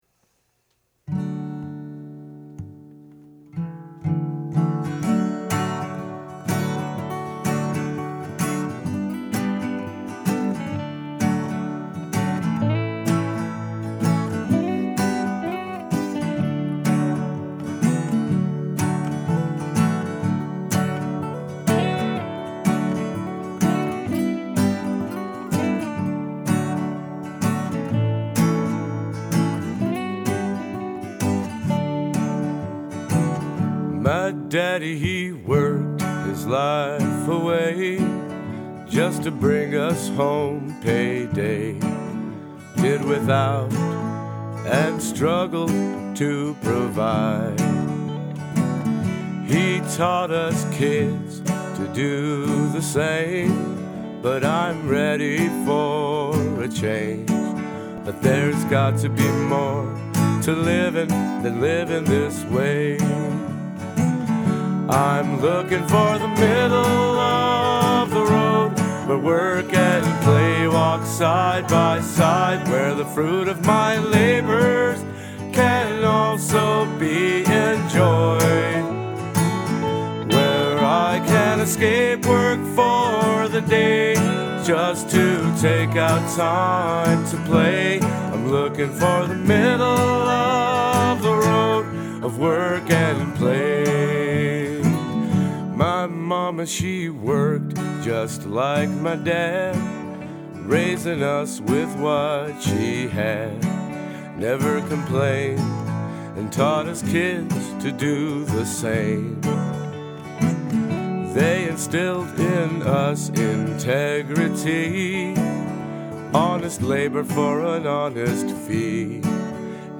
Song Only